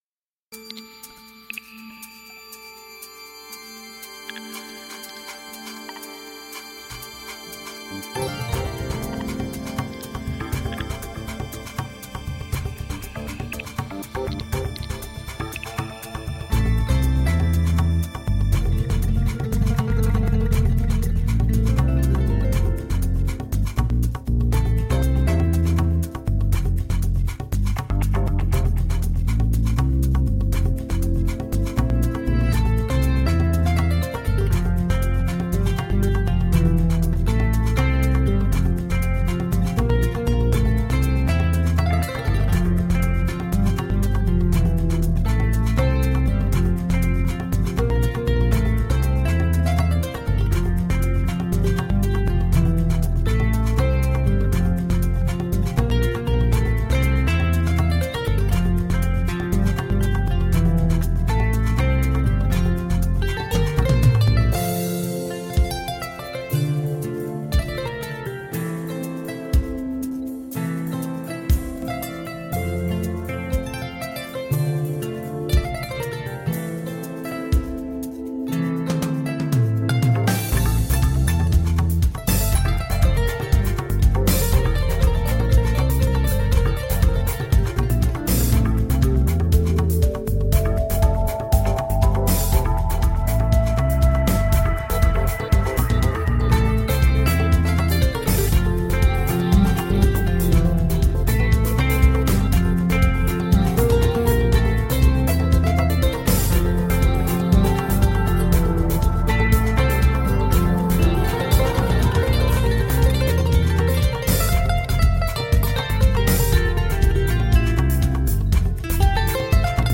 21st century ambient african kora.
A wonderfully laid-back album
kora